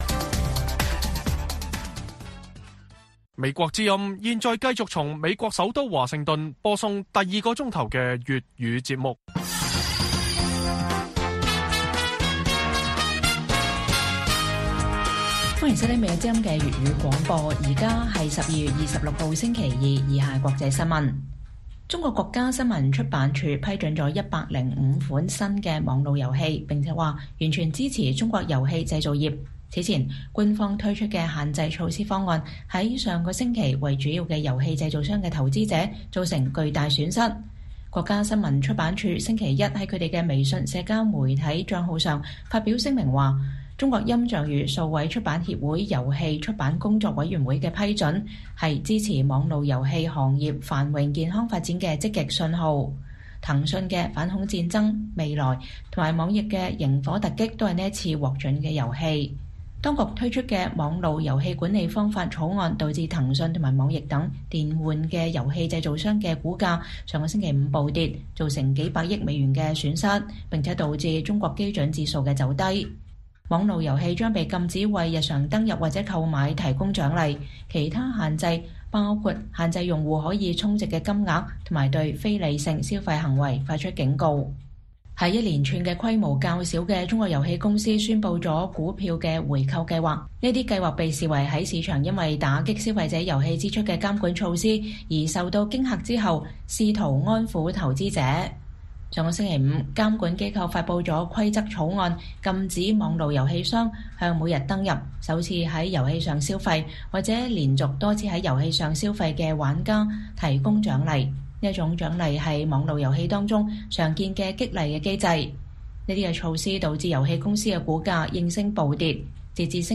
粵語新聞 晚上10-11點 : 澳反外國干預法定罪首例專家:中國收買或資助幹預介入選舉